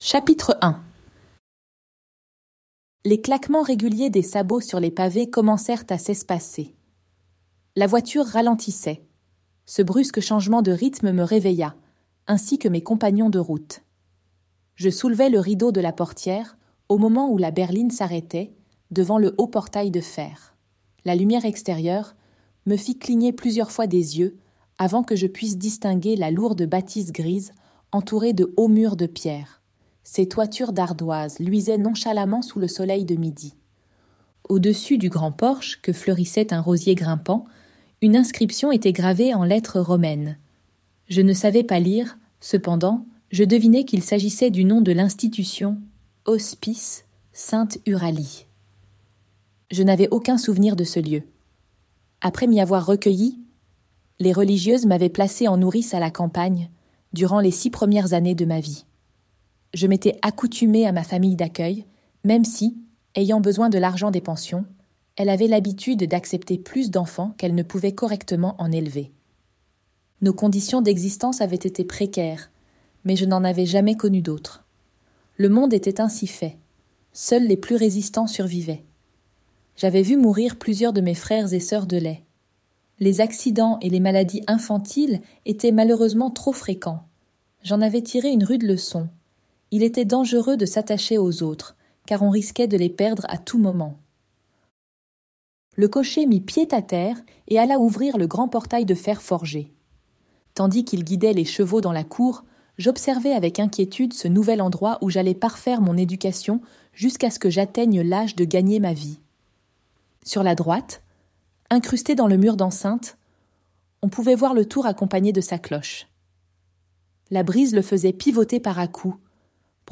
Ce livre audio a été enregistré en utilisant une synthèse vocale.